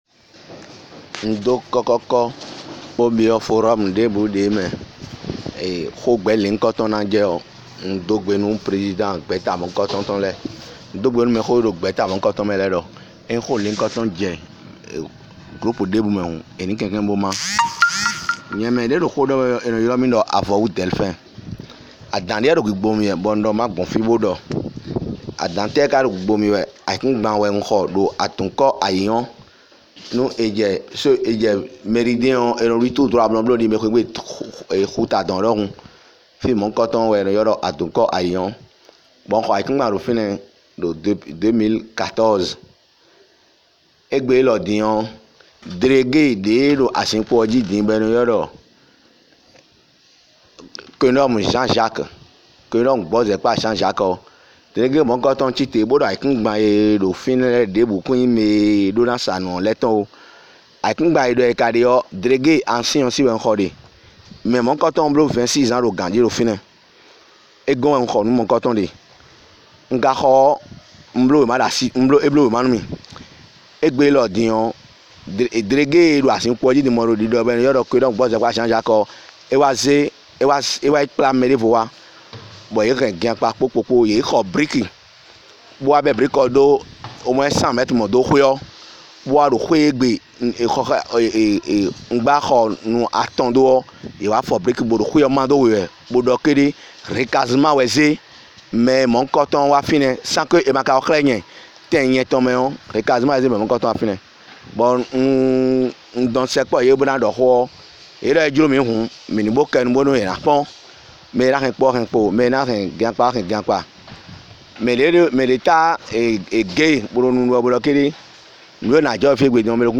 Écoutons l’audio d’une victime 👇👇👇